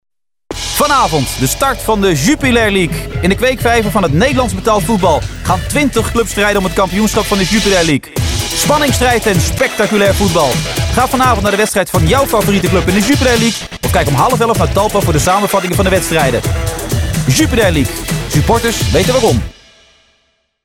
[Luister naar: Jingle aftrap] [Bekijk: uitzendschema Sky radio]